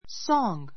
sɔ́ːŋ ソ ーン ぐ ｜ sɔ́ŋ ソ ン ぐ